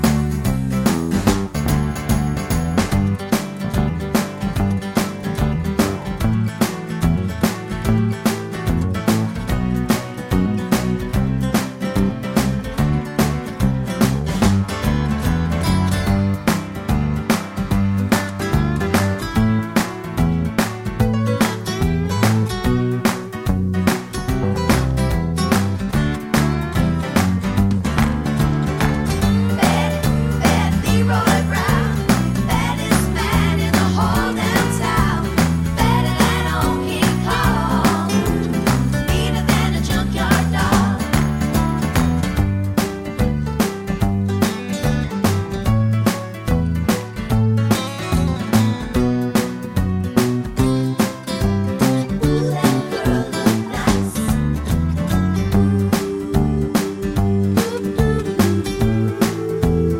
no Backing Vocals Country (Male) 3:04 Buy £1.50